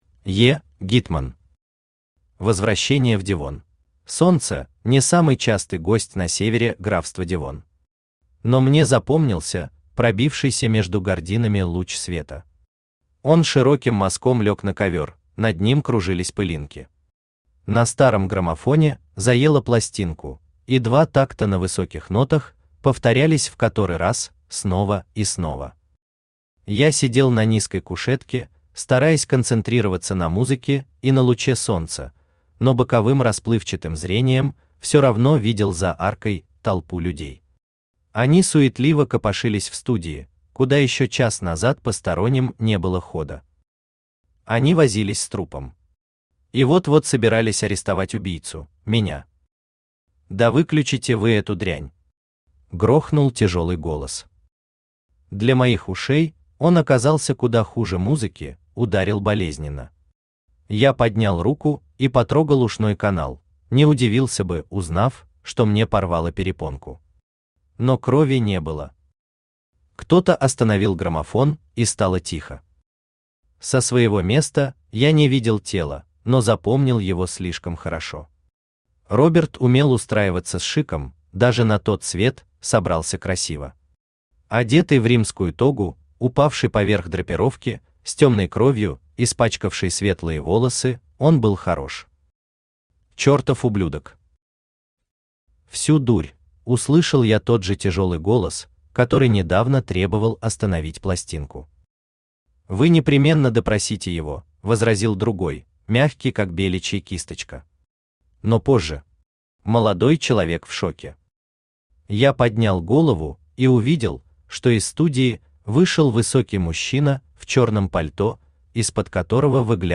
Аудиокнига Возвращение в Девон | Библиотека аудиокниг
Aудиокнига Возвращение в Девон Автор Е. Гитман Читает аудиокнигу Авточтец ЛитРес.